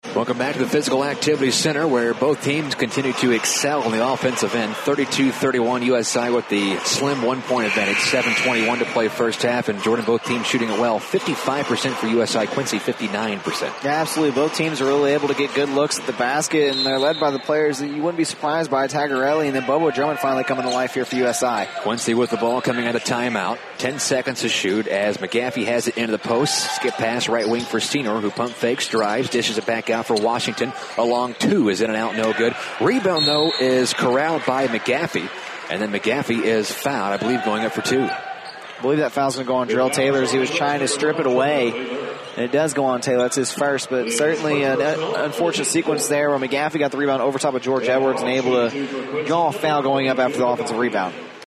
Best Audio Sports Play-By-Play sponsored by Comrex
Best-Play-by-play.mp3